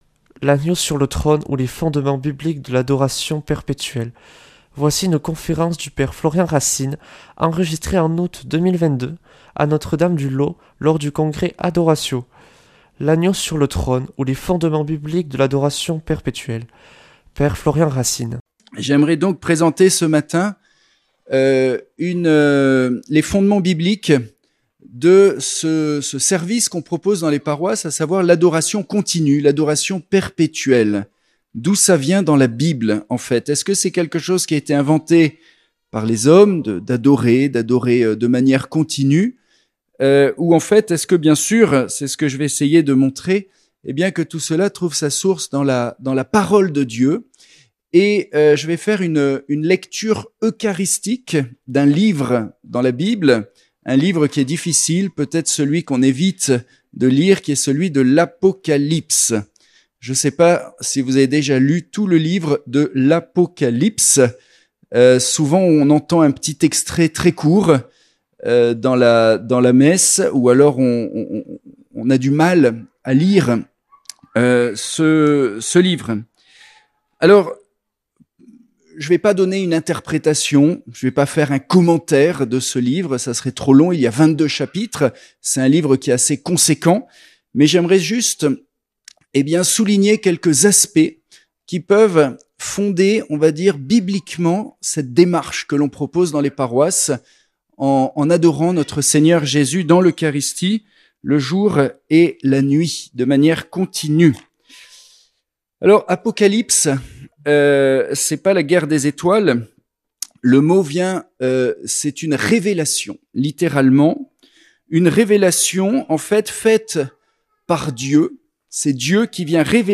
Une conférence
(Enregistrée en août 2022 à Notre-Dame du Laus lors du congrès Adoratio)